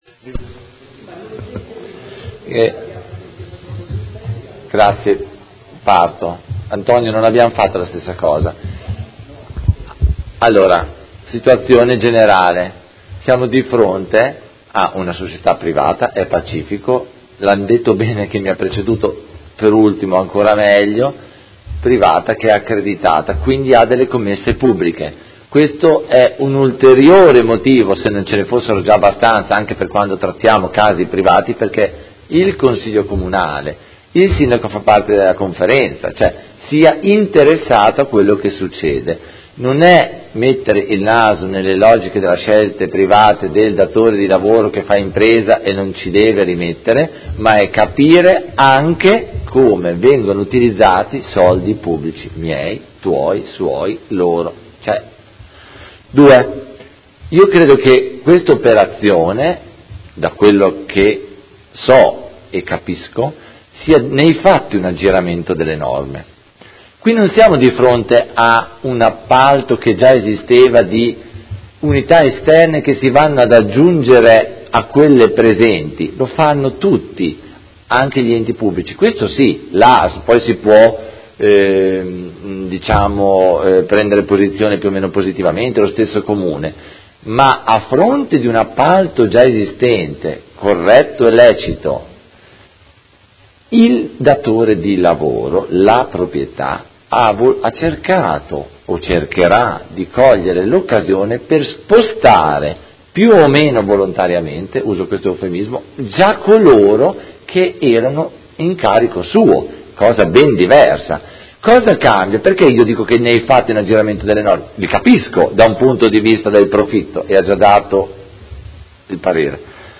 Seduta del 25/05/2017 Dibattito. Interrogazioni 61274 e 62840 sui lavoratori di Hesperia Hospital.